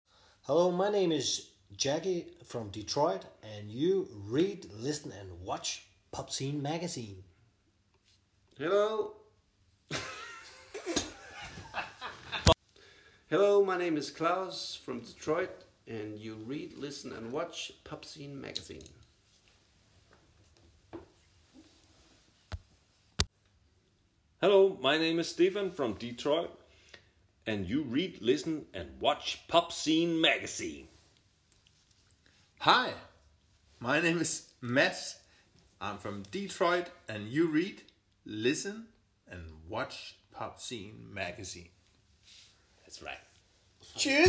Aufsager.mp3